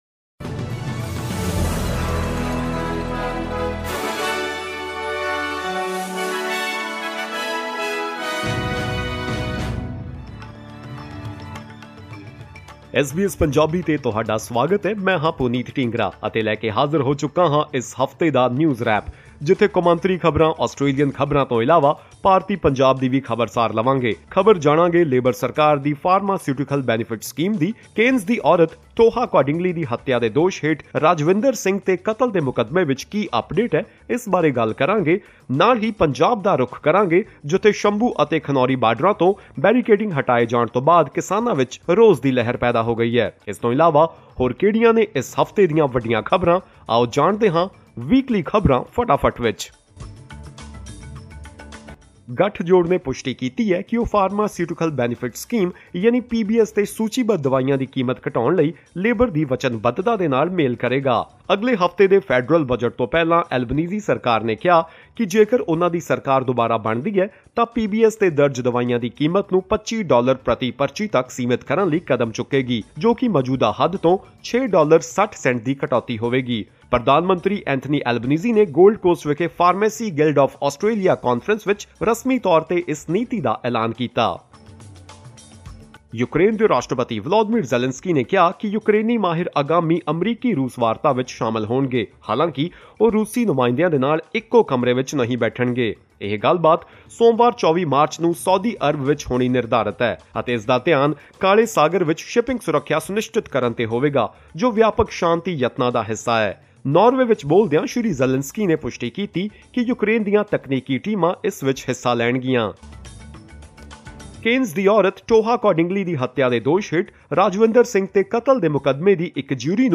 Presenting the Weekly News Wrap with the major international, Australian, and Indian news of the week.
Top news of the week.